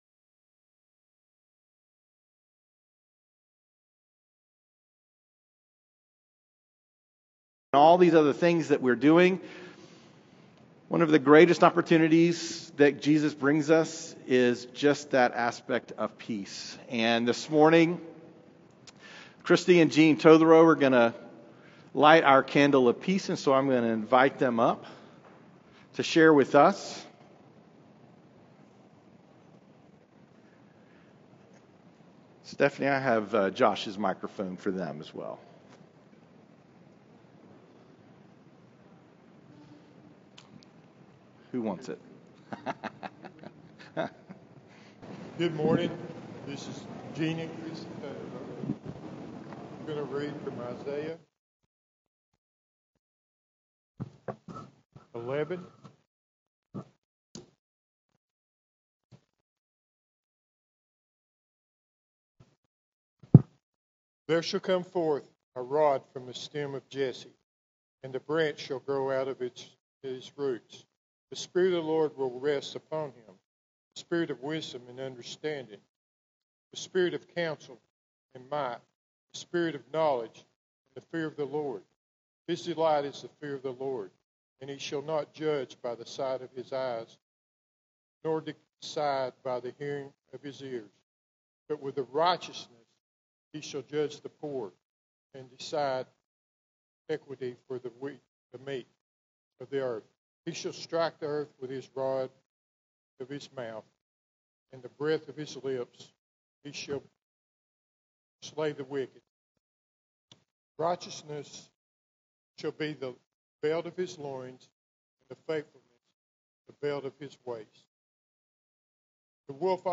Latest Sermon - Journey Church